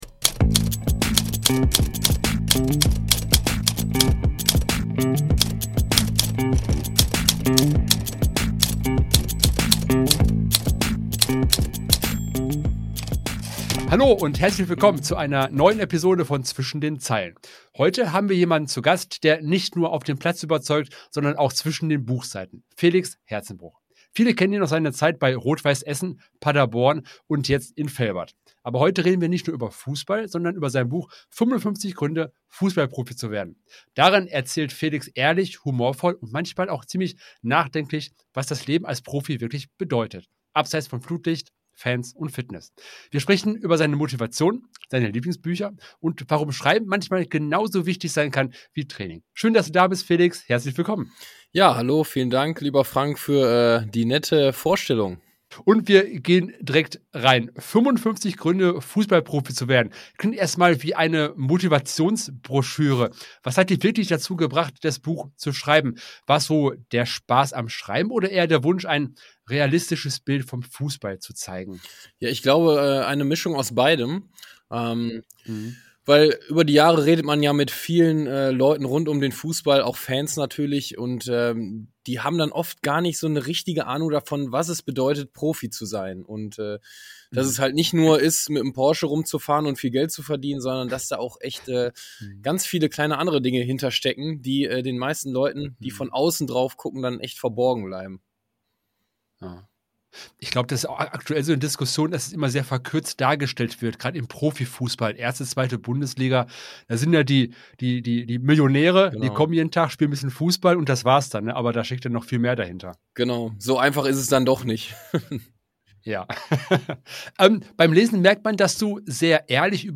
Am Ende gibt es natürlich noch eine kleine Lesung Also, Kaffee geholt und auf die Couch gemümmelt +++++++++++++++++++++++++++++++++++++++++++++++++++++++++++++++++++++++++++ Lass mir gern ein Abo da!